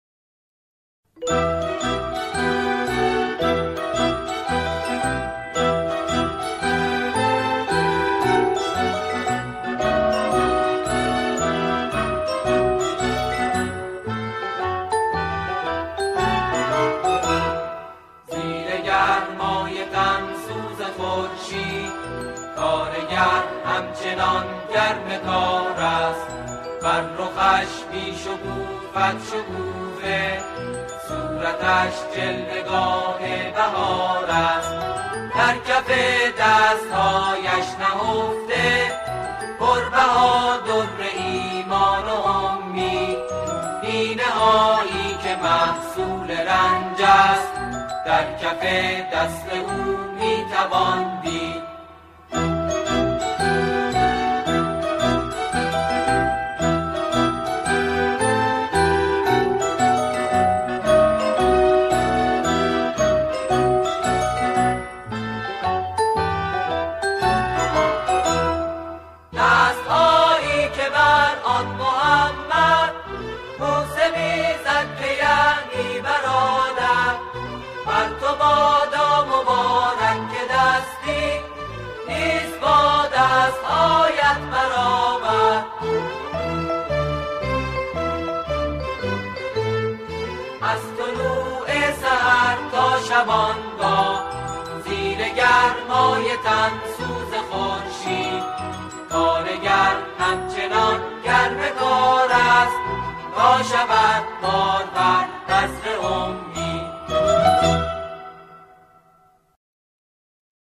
گروهی از جمعخوانان